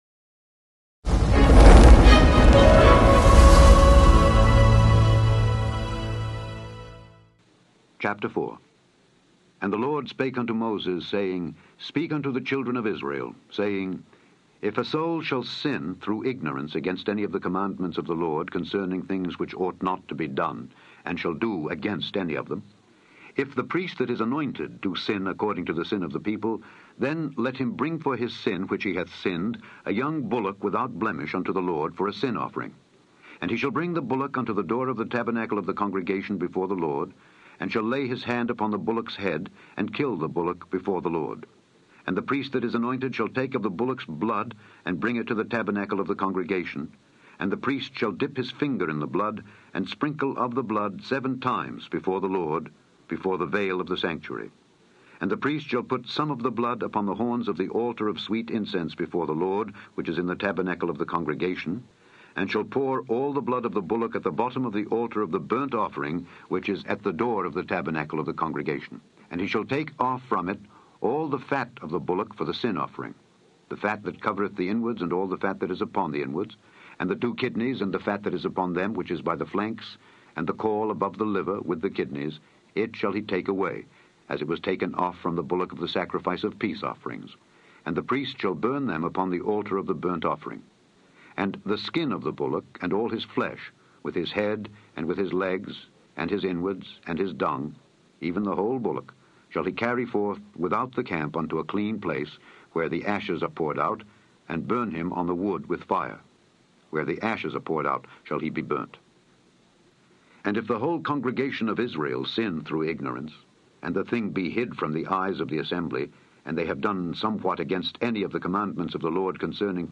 In this podcast, you can hear Alexander Scourby read Leviticus 4-6 to you.